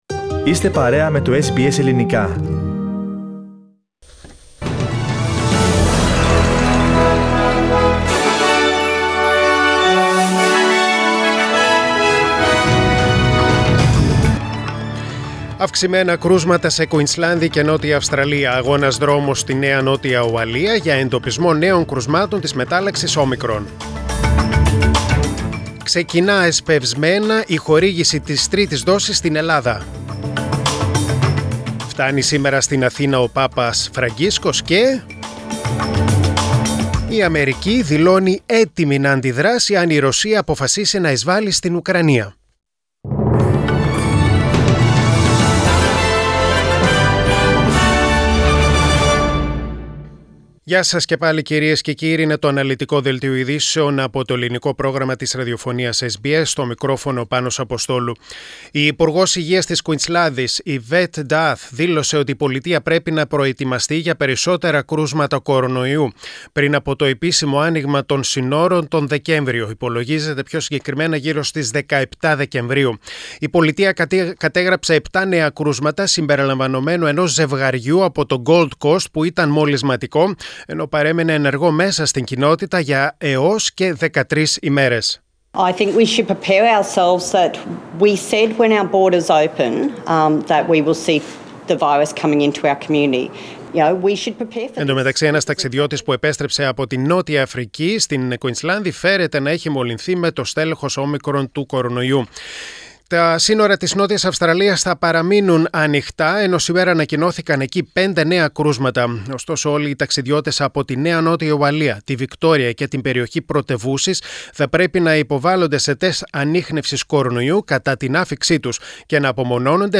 Press Play on the main photo and listen the News Bulletin (in Greek) Share